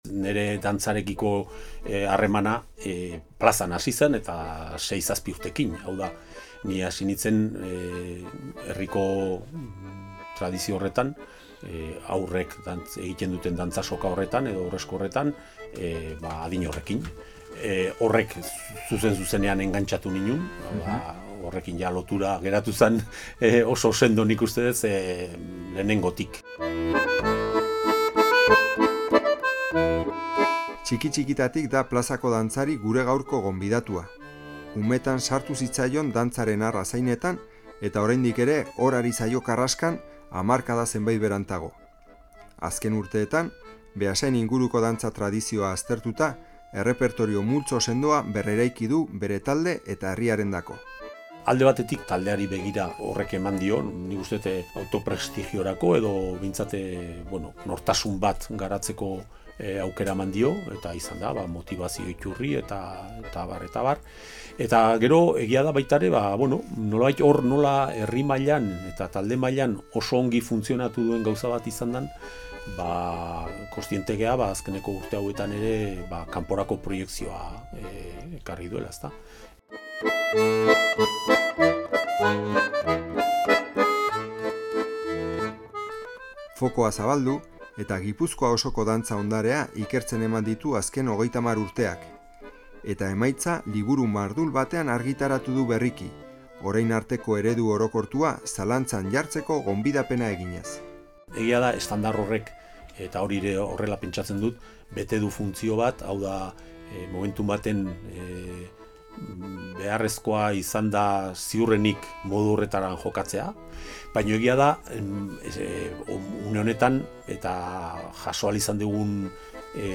Hasierako doinua : Aurtzaka (Kontrapas-agurra).